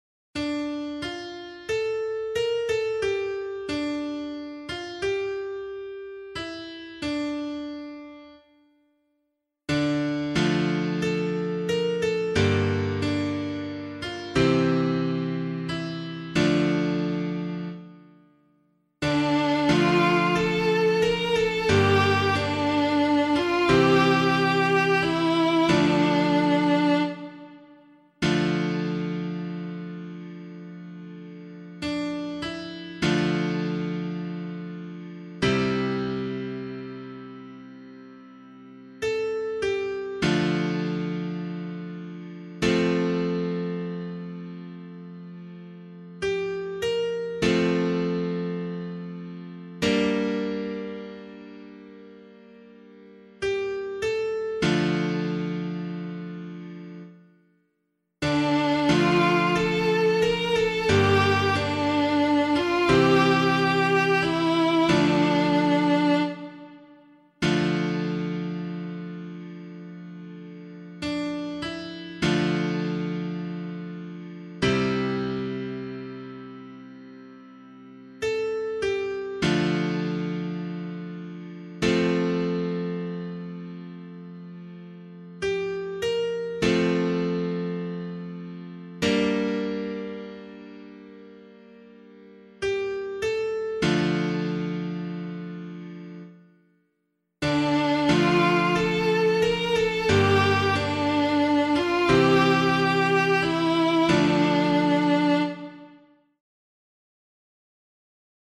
327 Assumption Day Psalm [LiturgyShare 1 - Oz] - piano.mp3